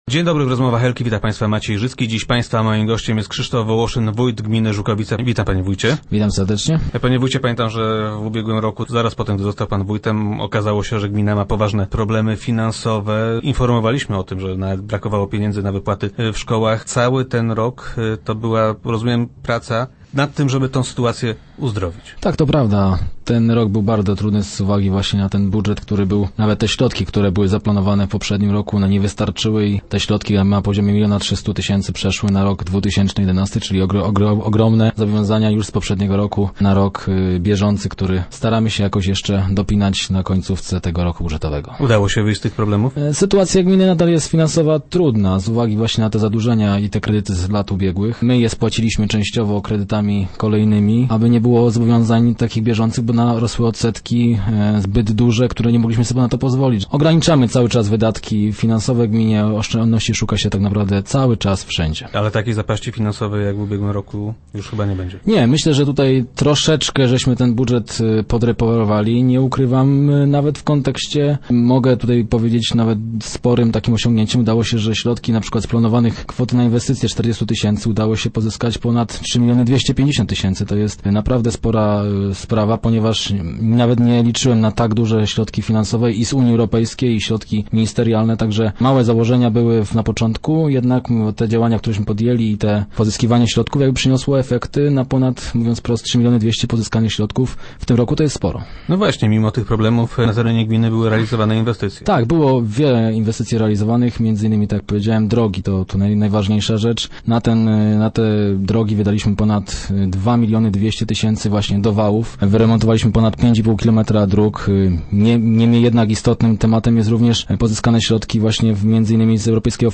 - Wychodzimy z finansowego dołka, ale ciągle jeszcze mamy długi - mówi Krzysztof Wołoszyn, wójt gminy Żukowice, który był gościem Rozmów Elki.